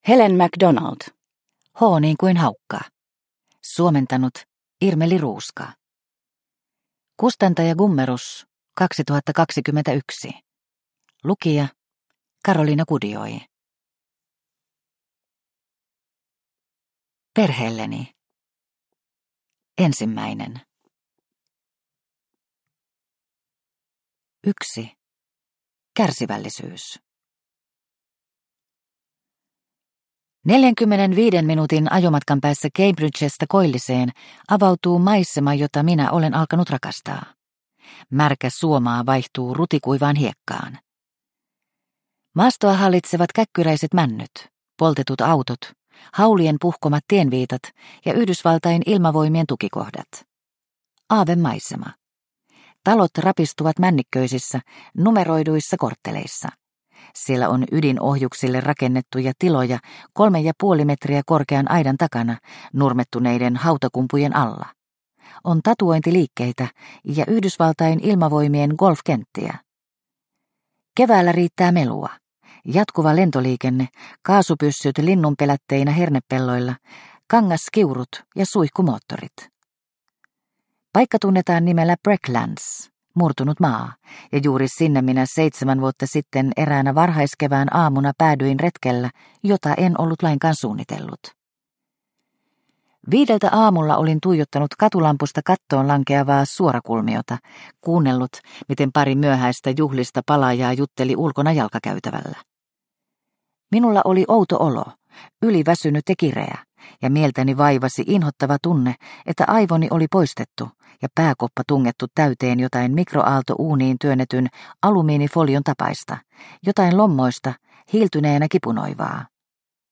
H niin kuin haukka – Ljudbok – Laddas ner